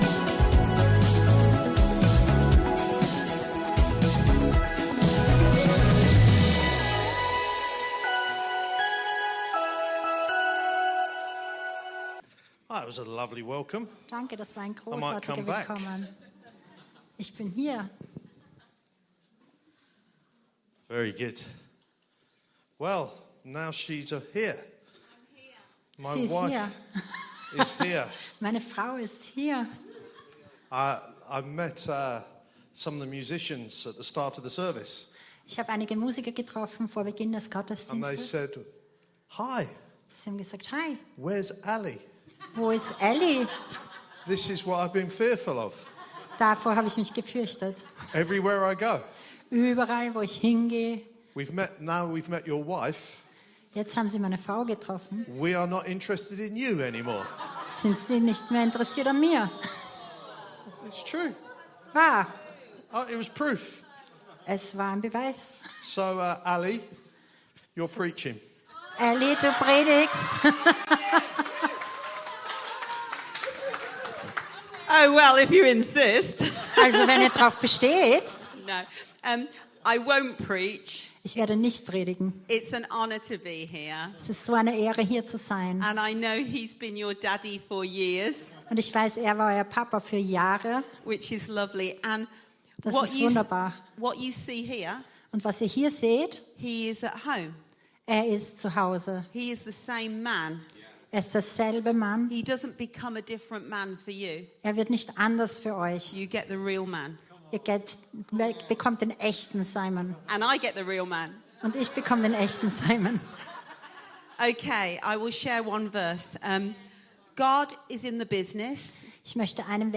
Gottesdienst live aus der LIFE Church Wien.